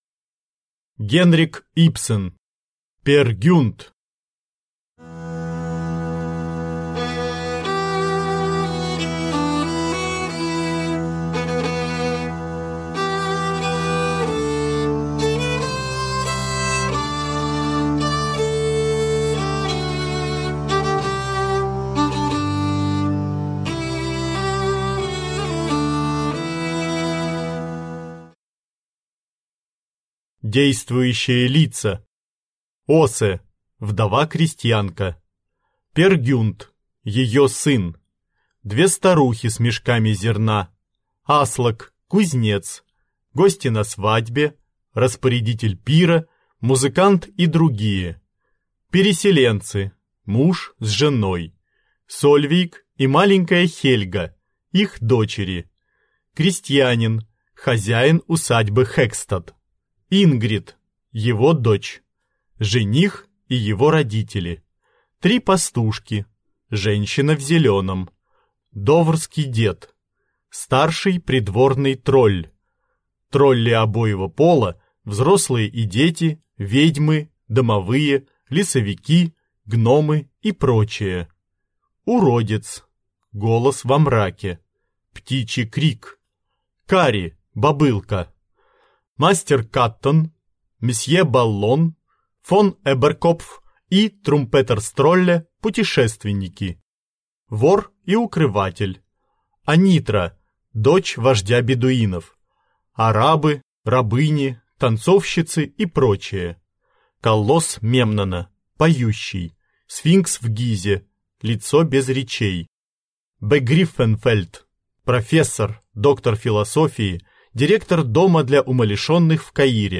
Аудиокнига Пер Гюнт | Библиотека аудиокниг